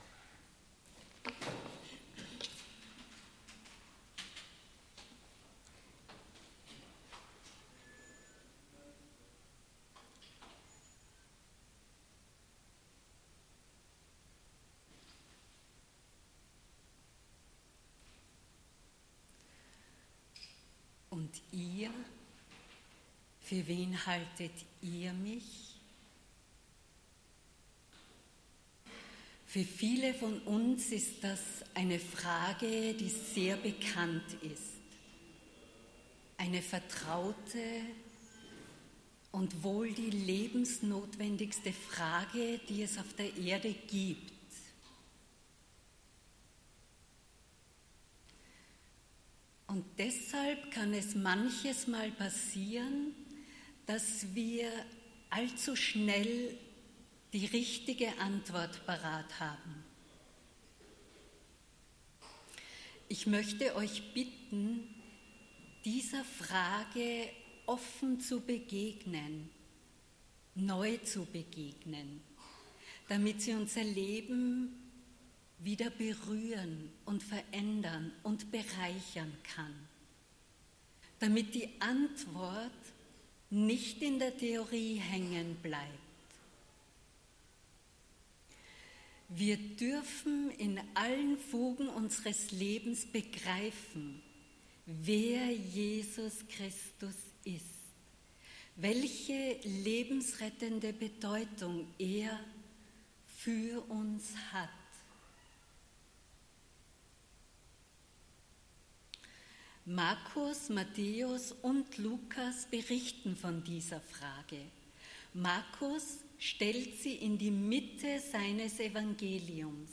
Predigt vom 19.06.2016